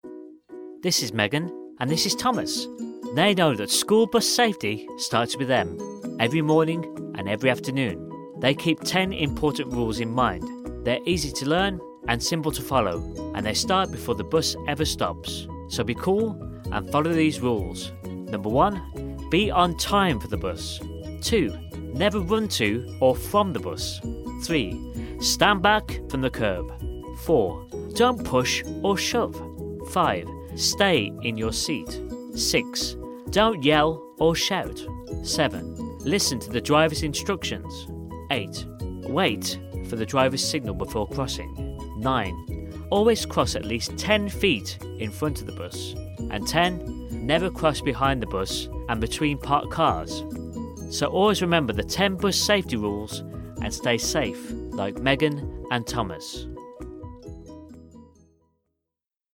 A dynamic British male voice. Positive, friendly and sincere with subtle Midlands tones.
E-Learning